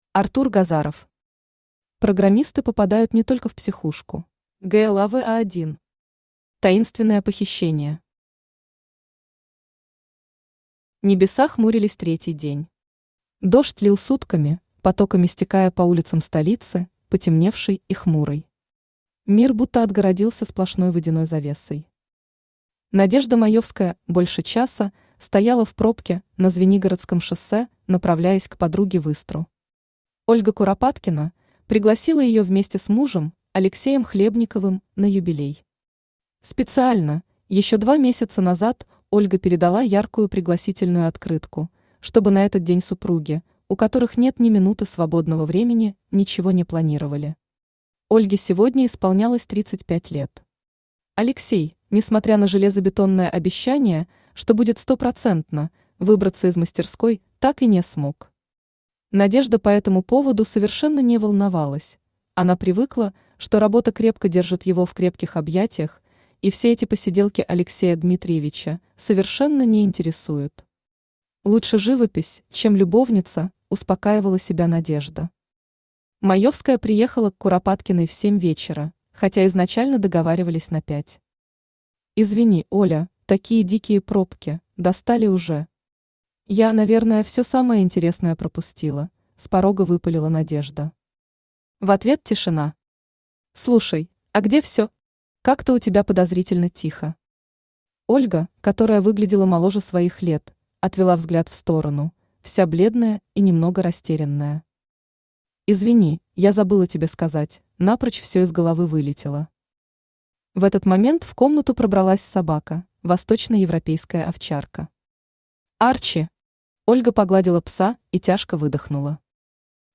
Аудиокнига Программисты попадают не только в психушку | Библиотека аудиокниг